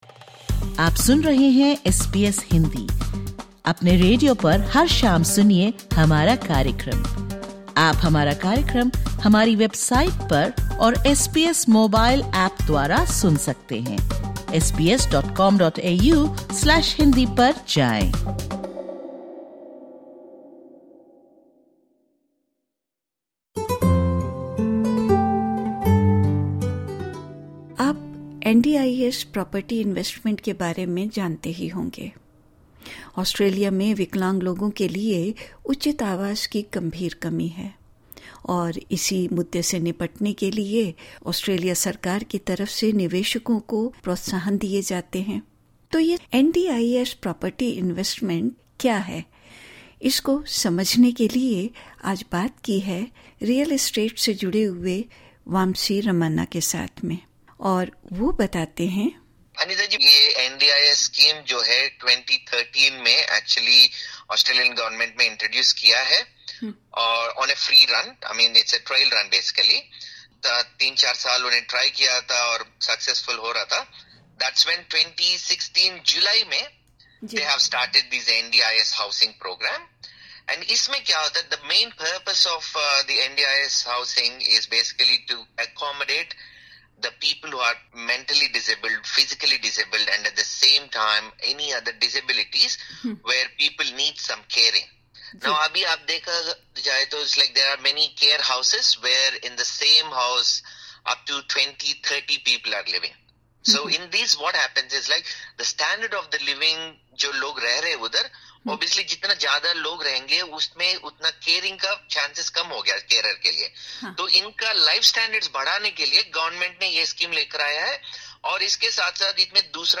अस्वीकरण: इस साक्षात्कार में व्यक्त की गई जानकारी सामान्य प्रकृति की है।